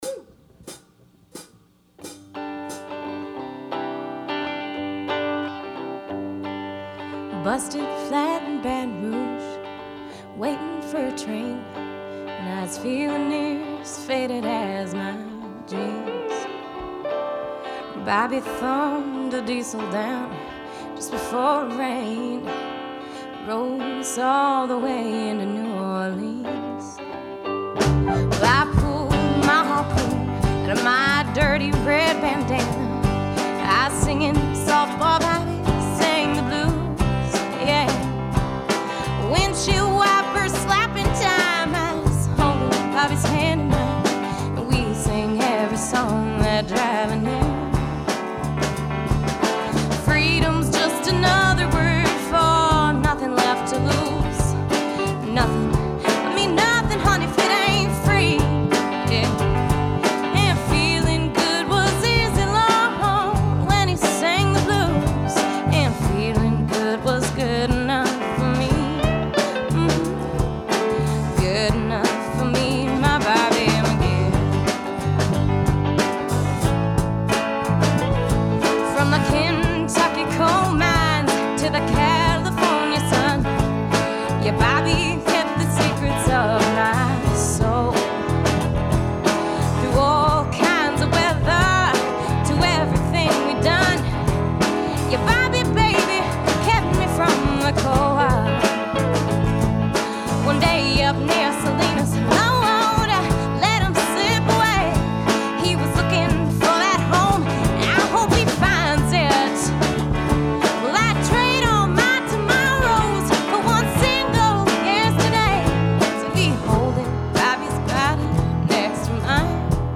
We had the monumental task of mixing 4 tracks to mono to allow three more tracks of overdubs.  I felt that the guitar part, in particular, needed a 'dynamic sensitivity' relative to the vocal, two acoustic guitars - nashville tuned - were OD'd plus a lead electric for 'obbligato.'
We achieved a more balanced mix with just a hint of 'verb and got to play with the graphic EQ on the mix buss.
Me and BobbyMcGee (multiband & mid-side processed)